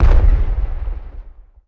1 channel
EXPLO10.mp3